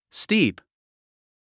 発音
stíːp　スティープ